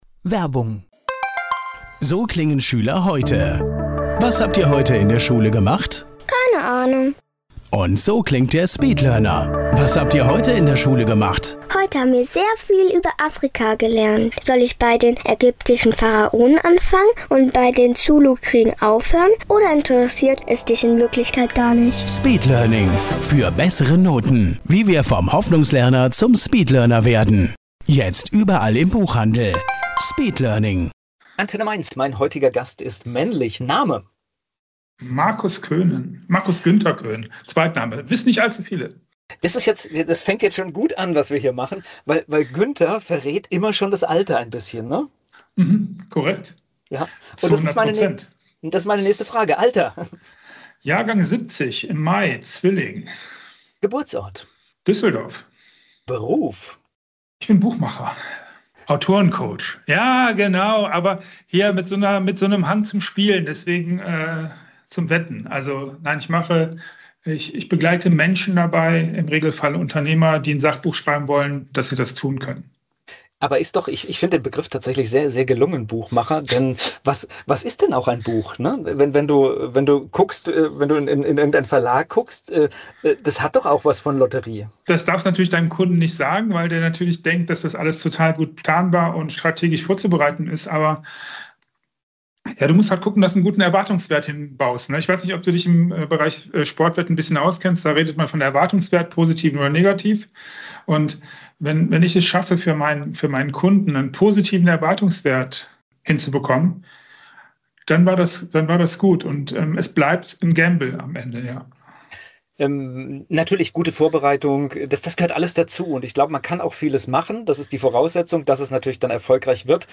Ein Gespräch voller persönlicher Einblicke, klarer Gedanken und überraschender Wendungen.